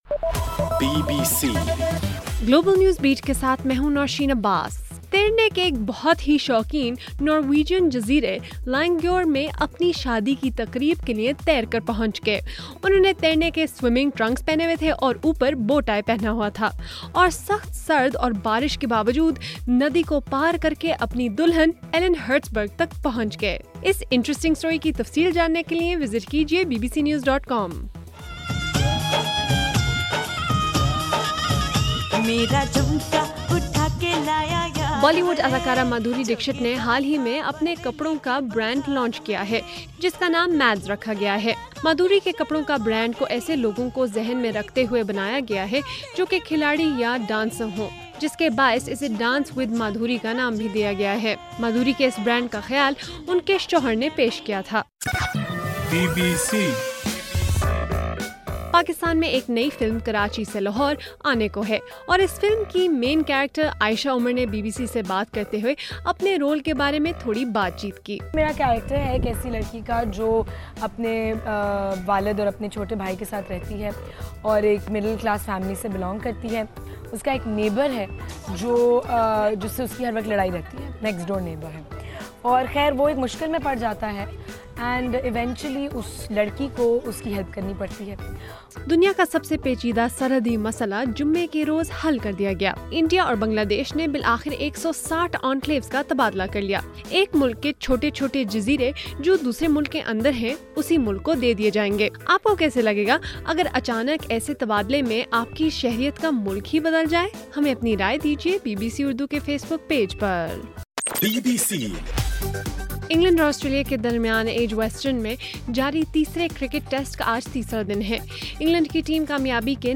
جولائی 31: رات 8 بجے کا گلوبل نیوز بیٹ بُلیٹن